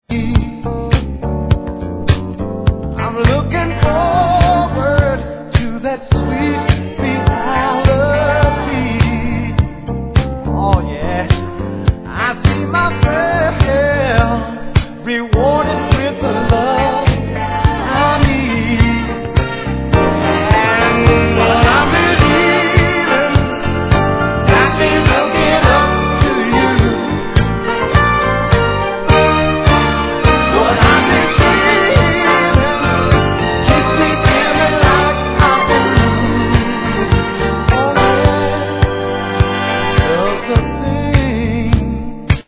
Tag       DANCE CLASSICS OTHER